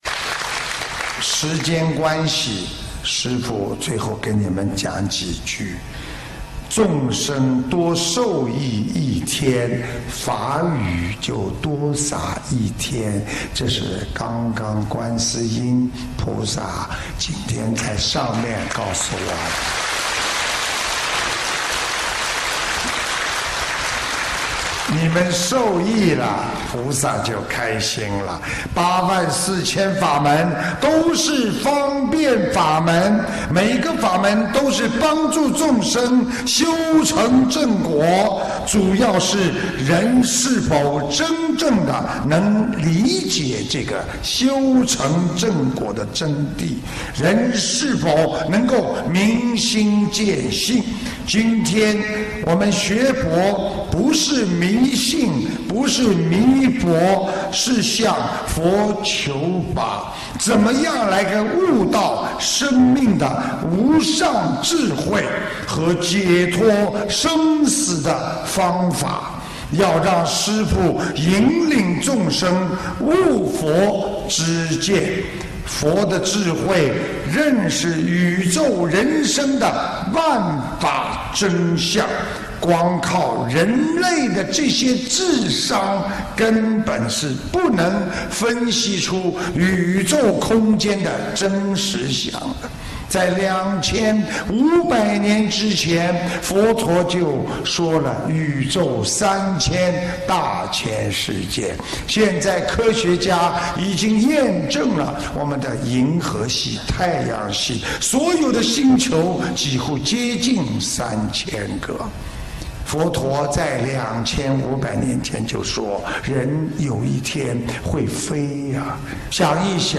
马来西亚槟城